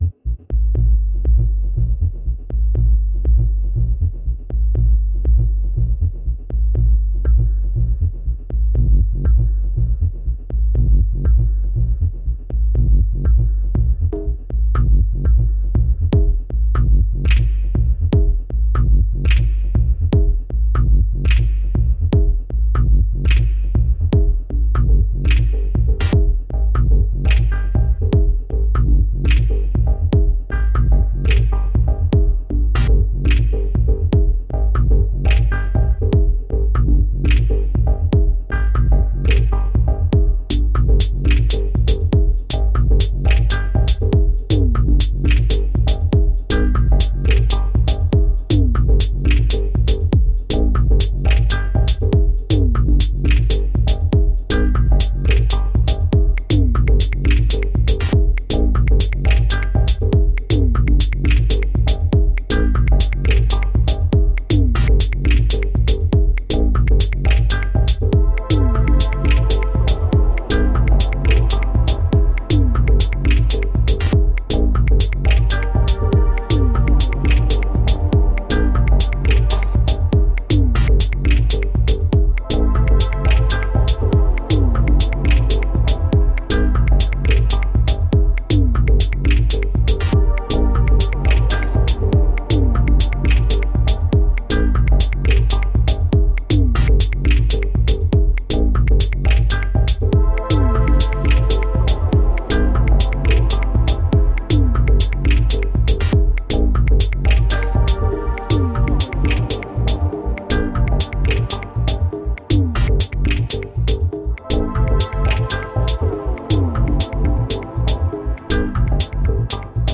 Deep house and tech with a trippy downtempo vibe.
Classic drum machines mingle with modern minimal textures